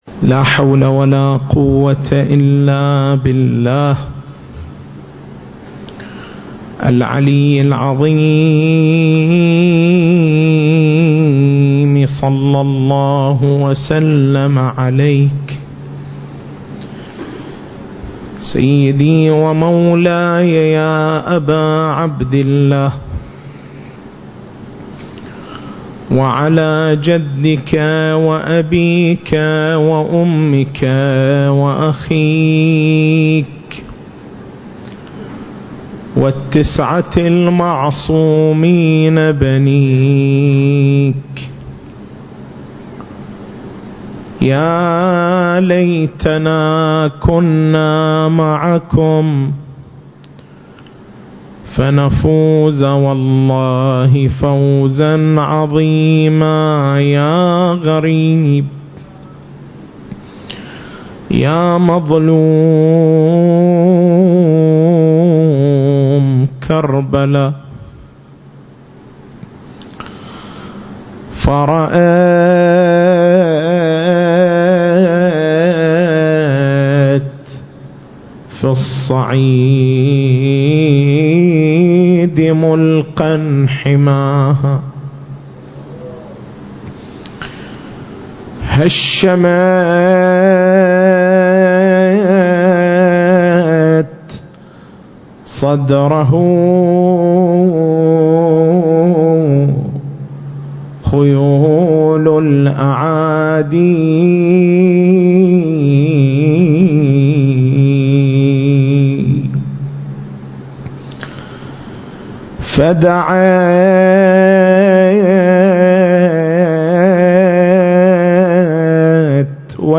- دلالة هذه الأحاديث على وجود الإمام المهدي (عجّل الله فرجه) المكان: حسينية الحاج حبيب العمران التاريخ: 1440 للهجرة